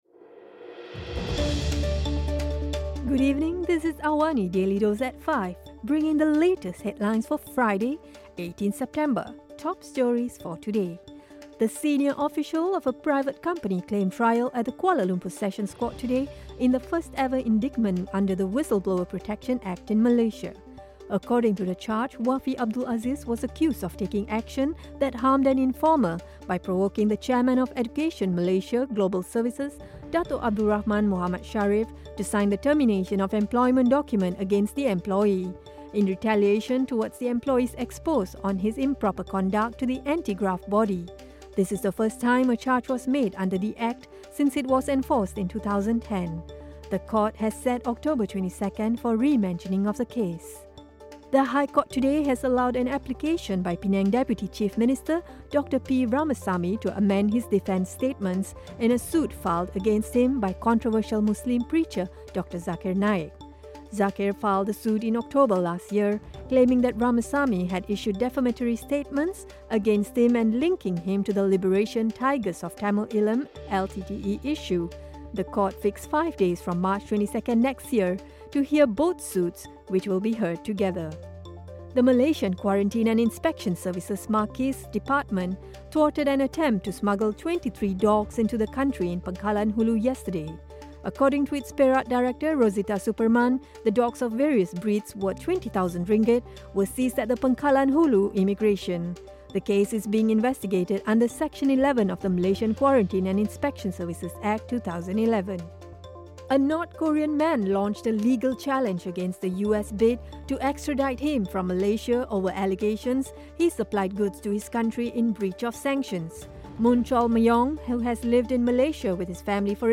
Listen to the top stories of the day, reporting from Astro AWANI newsroom — all in three minutes.